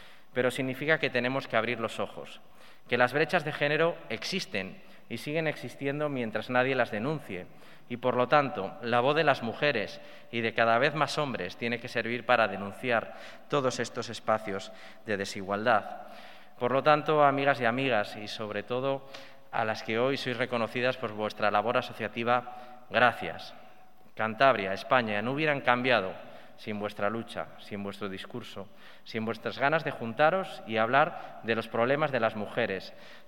Audio del vicepresidente de Cantabria, Pablo Zuloaga
El presidente de Cantabria, Miguel Ángel Revilla, y el vicepresidente, Pablo Zuloaga, han tomado la palabra en el acto para reivindicar, entre otras cosas, el papel esencial de las mujeres durante la pandemia en diferentes ámbitos como los hospitales, los centros de salud, las residencias de mayores o los hogares.